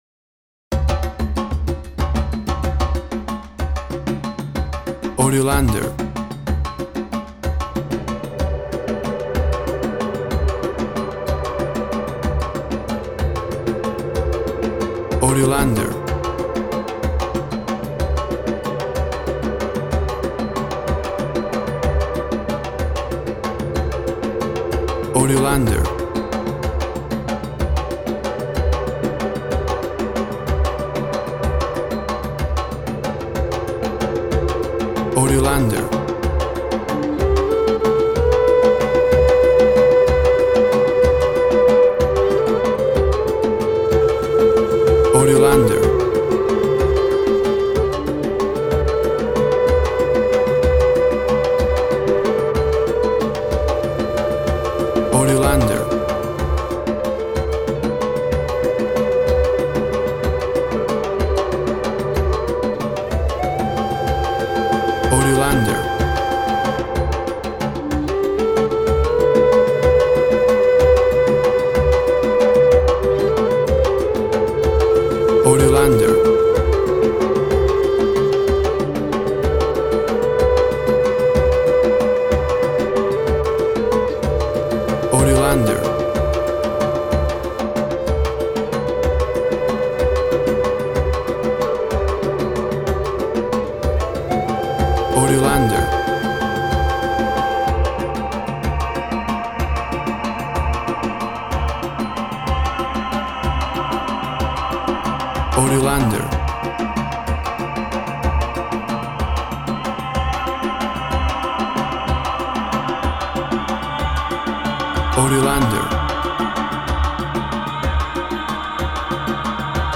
Africa drums. Epic and mesmerizing.
Tempo (BPM) 63